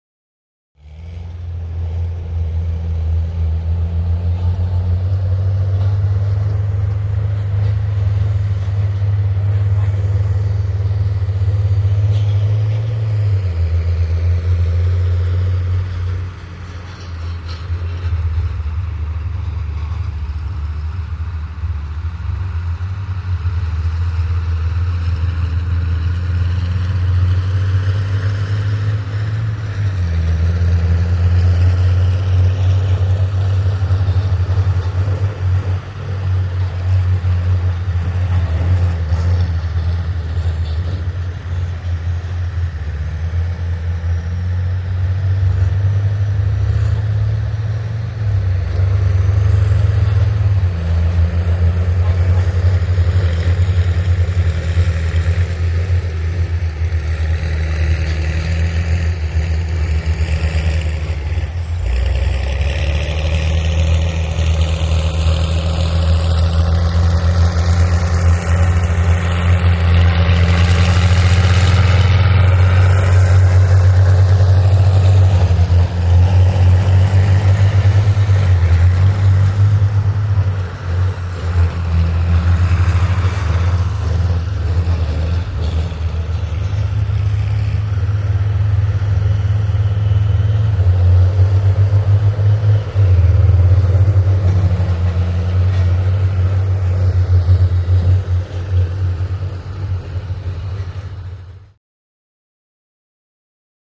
Bulldozers | Sneak On The Lot
Quarry; Quarry Atmosphere. Many Bulldozers, Trucks And Dump Trucks At Work In Long Shot, Mid Shot And Close Shot.